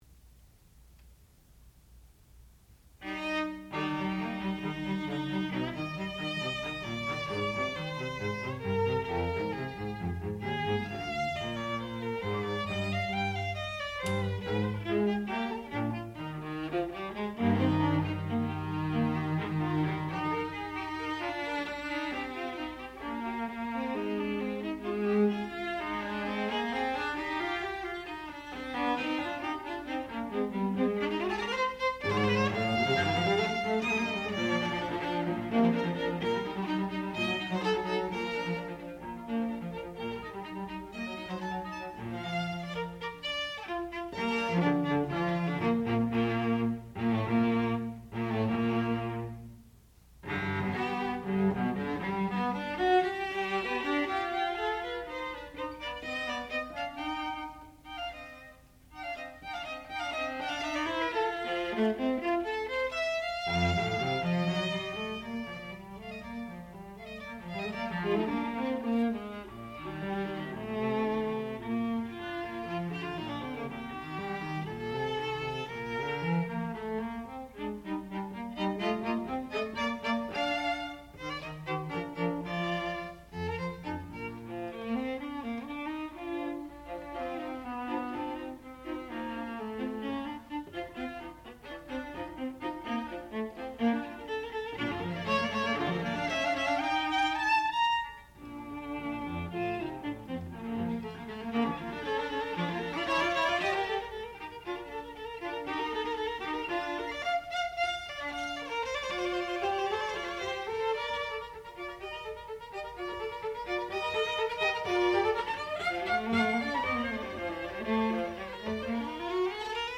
sound recording-musical
classical music
violoncello
Junior Recital
viola